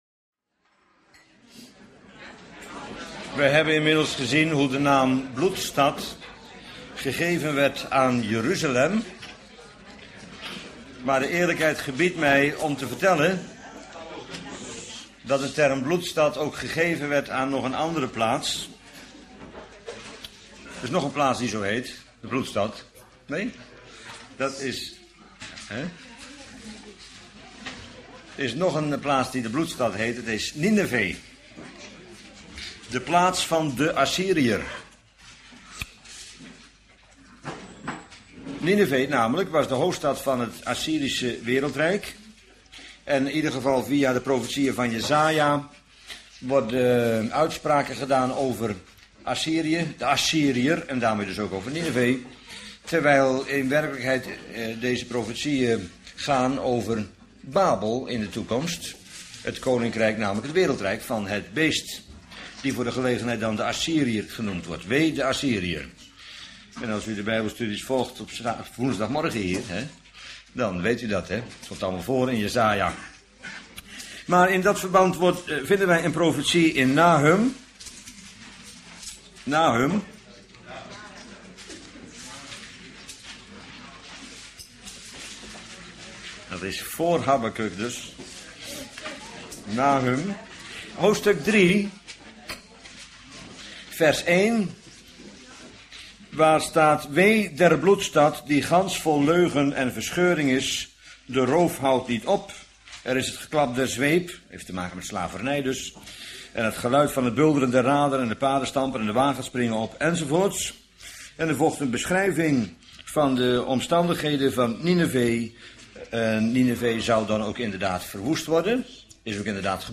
Bijbelstudie lezingen